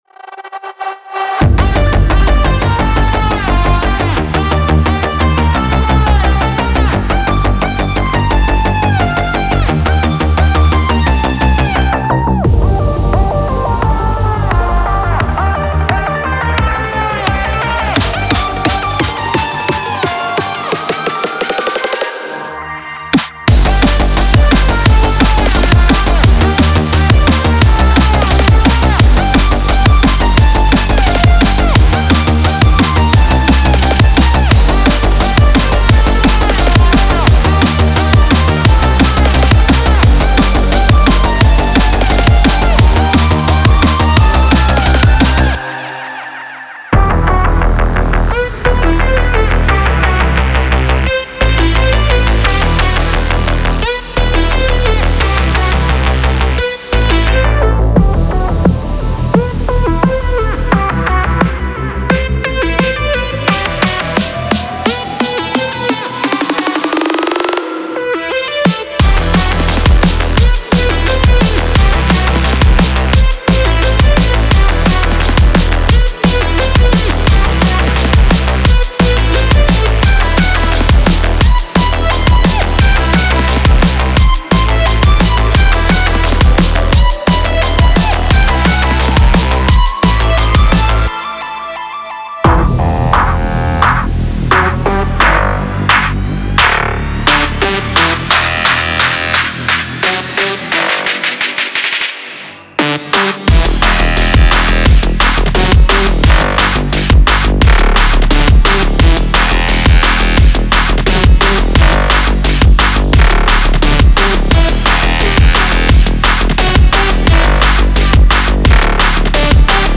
Genre:Drum and Bass
まず目を引くのは、雰囲気を演出する広がりのあるアトモスフェリック・ループ。
そこに力強いベースラインや迫力あるドラムループが加わり、リズムセクションを思いのままに構築できます。
リード、アルペジオ、コード、パッドなど、多彩な音色が収録されています。
さらに、ライザーやインパクト、ダウンシフター、ビルドアップといったFXでトラックに表情を加えることもできます。
デモサウンドはコチラ↓